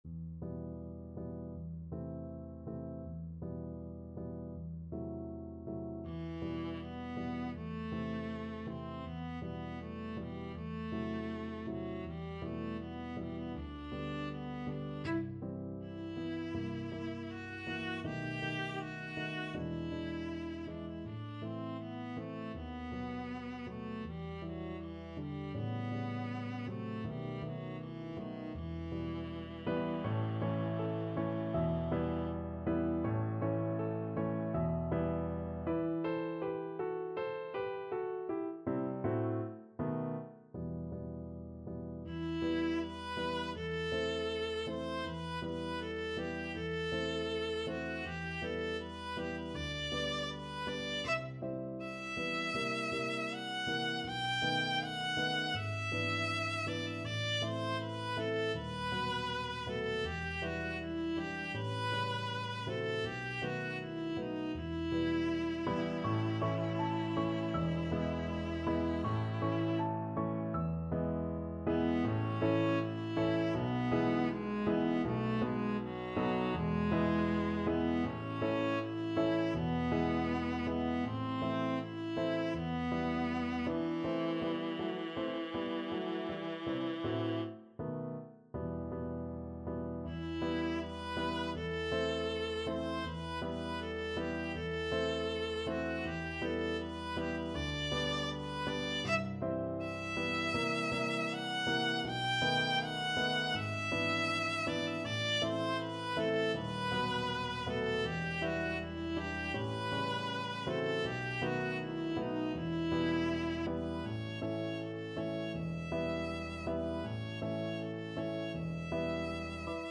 Viola
E minor (Sounding Pitch) (View more E minor Music for Viola )
~ = 100 Andante
2/4 (View more 2/4 Music)
Classical (View more Classical Viola Music)
jarnefelt_berceuse_VLA.mp3